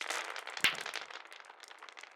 弹珠1.wav